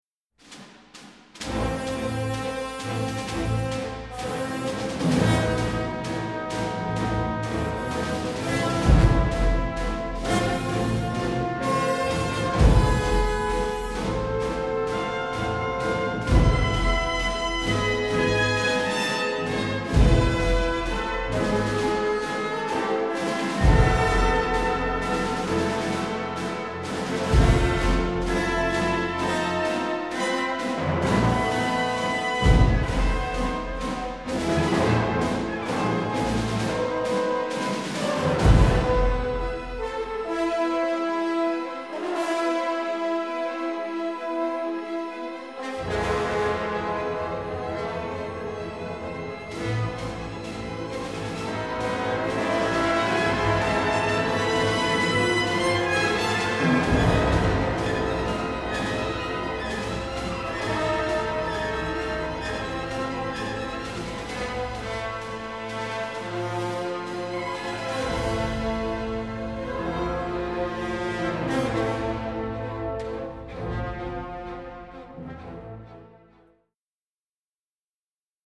Trumpets, French horns keep everything at peak excitement.
Recorded in Germany.